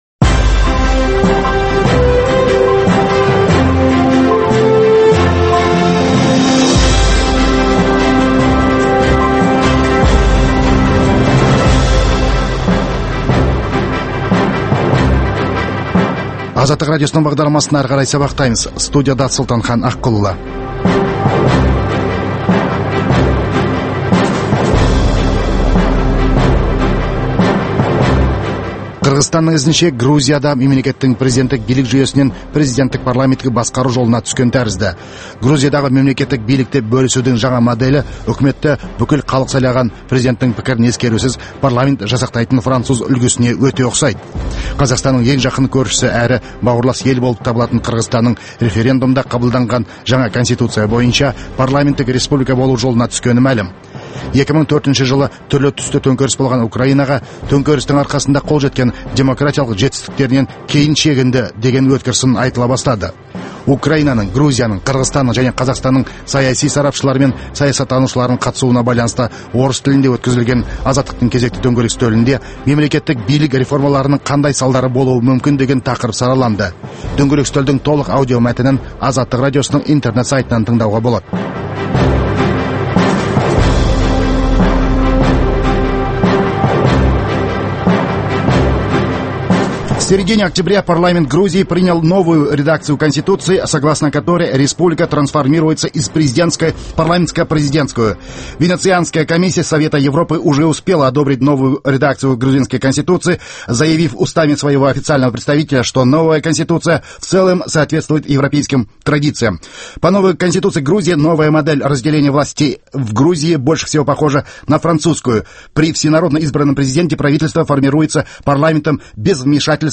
Дөңгелек үстел